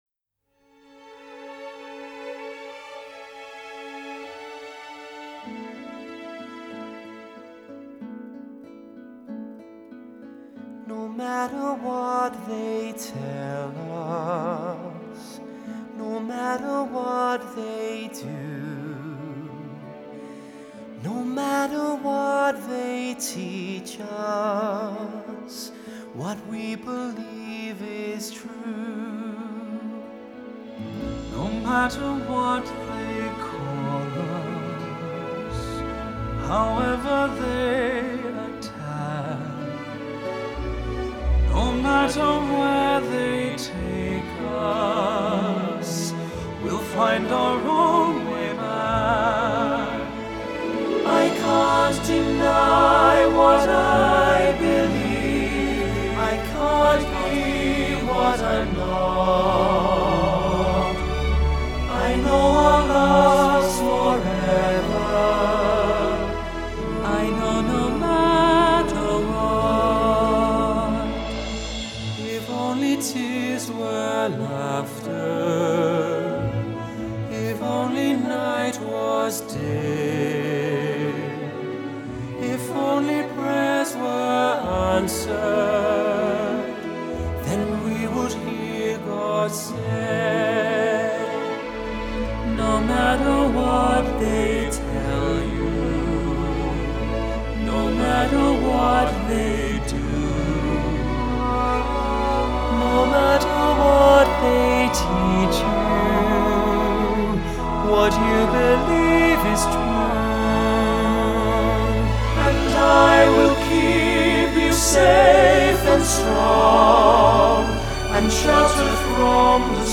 Genre: Vocal, Pop, Classical